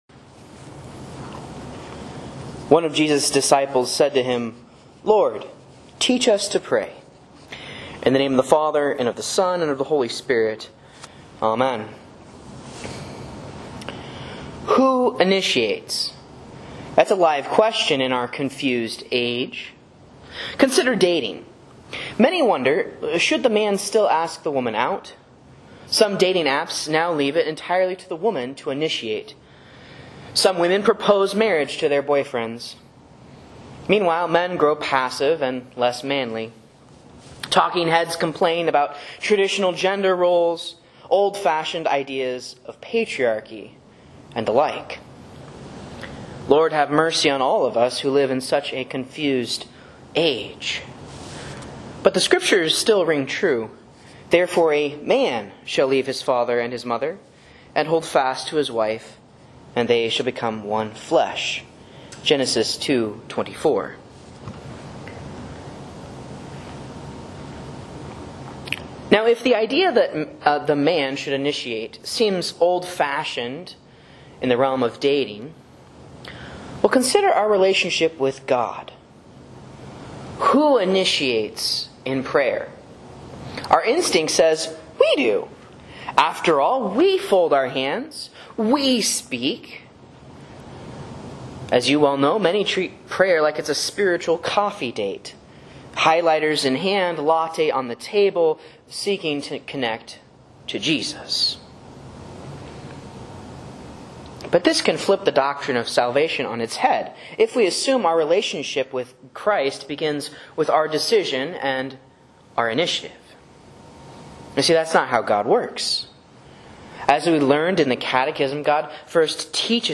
Sermons and Lessons from Faith Lutheran Church, Rogue River, OR
A Meditation on Luke 11:1b for Proper 12 (C)